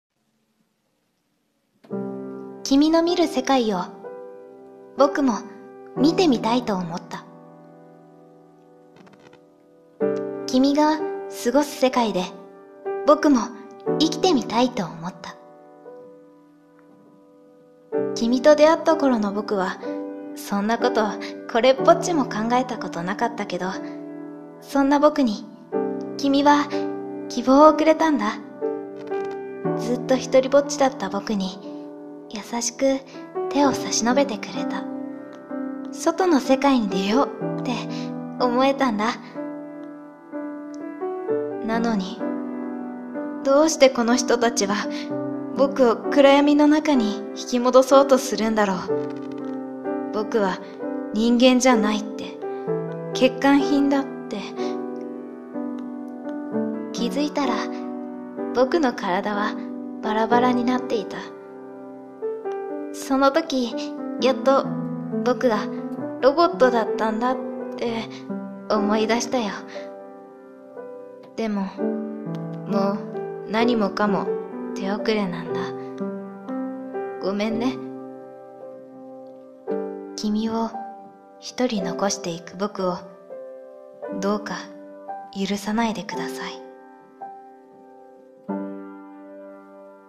［声劇台本］人間に恋したロボットの話